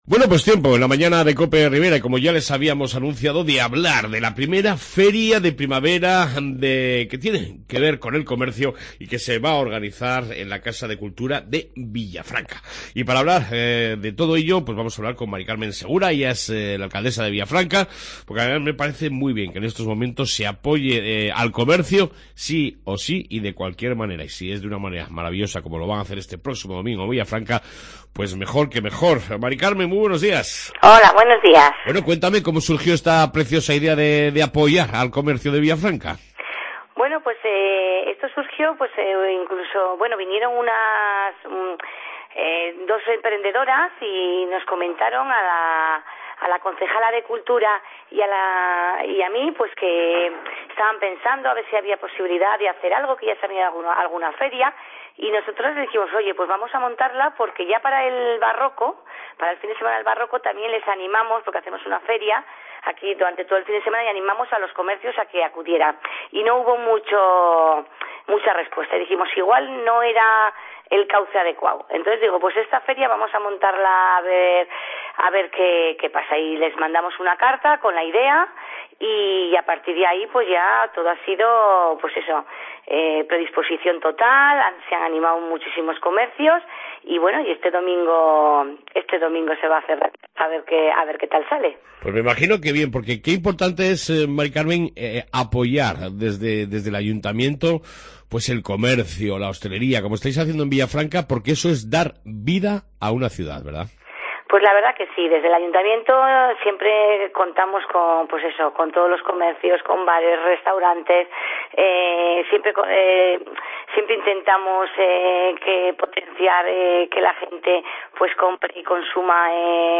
AUDIO: Entrevista con Mª Carmen Segura (Alcaldesa de Villafranca) sobre la 1ª Feria de Primavera del Comercio en Villafranca